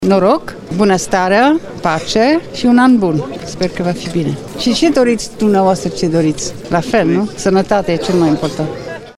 Într-un cadru mai puţin formal Majestatea Sa Margareta a subliniat că sănătatea este bunul cel mai de preţ.